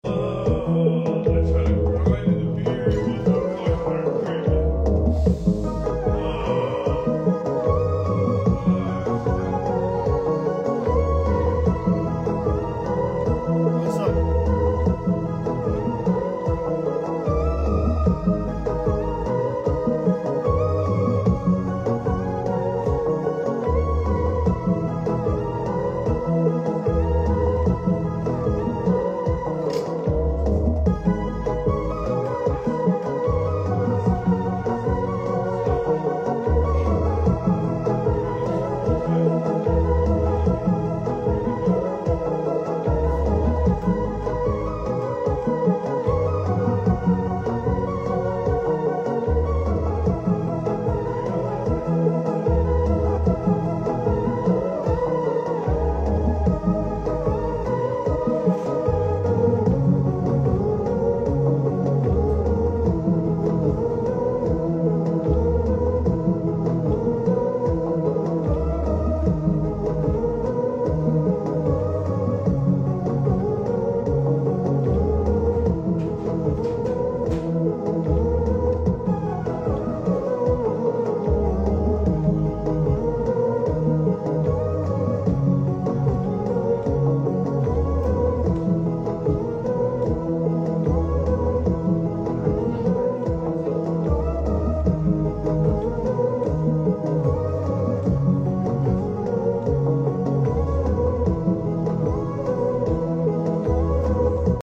a lil freestyle